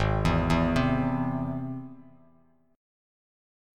Ab+ Chord
Listen to Ab+ strummed